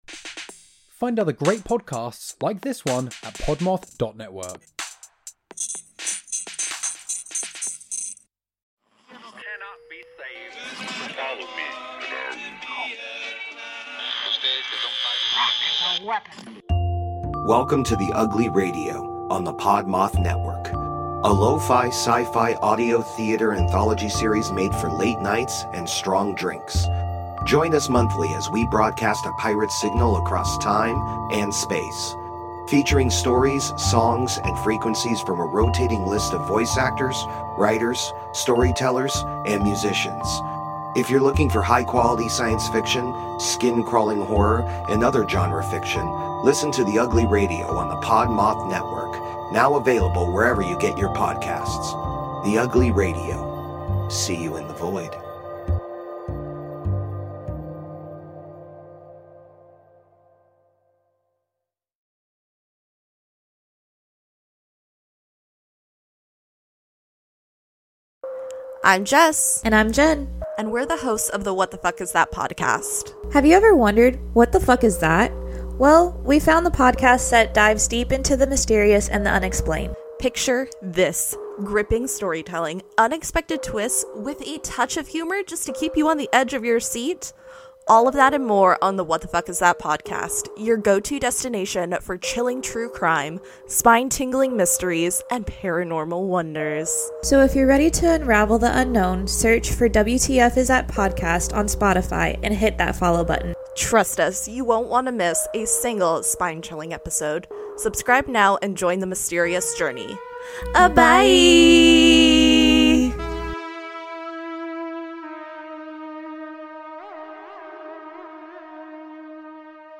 This is the podcast where we talk about brutal crimes, bizarre occurrences, and get you drunk with cocktails themed around one of our stories. Our story tellers are a mom and son combo who will bring you brutal and bizarre stories in a unique and fun way.